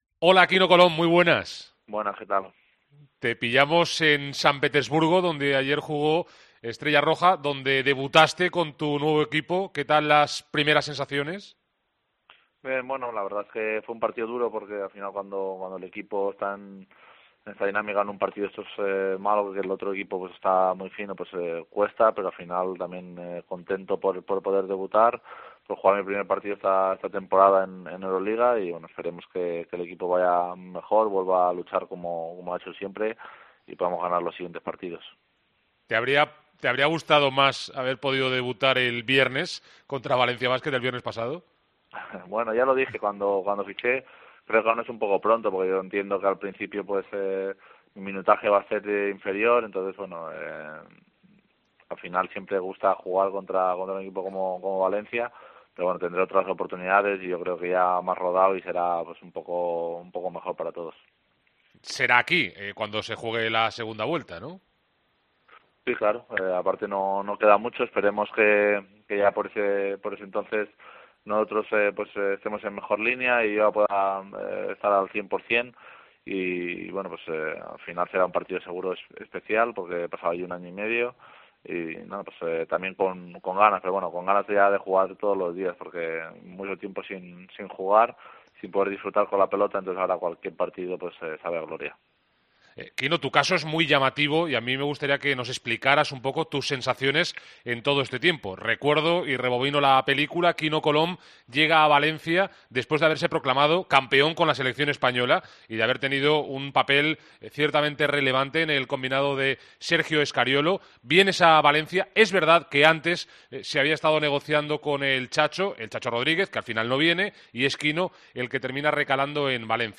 AUDIO. Entrevista a Quino Colom en Deportes COPE Valencia